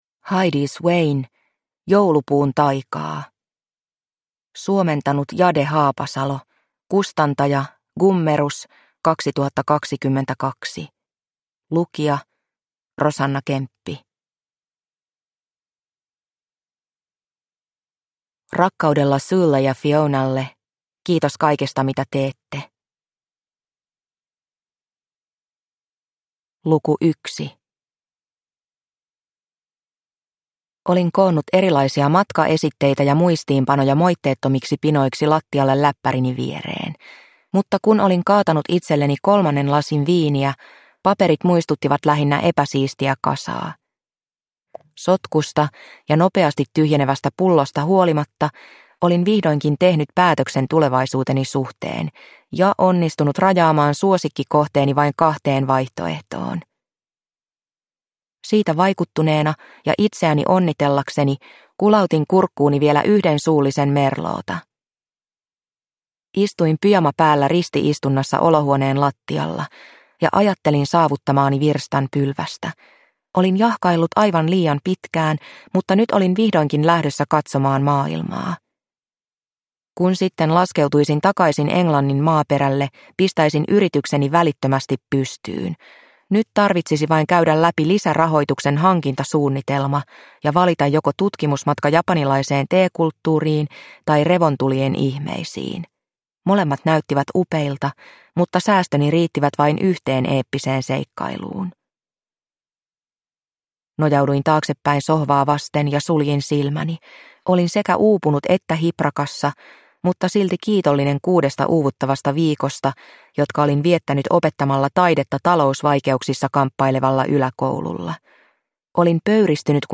Joulupuun taikaa – Ljudbok – Laddas ner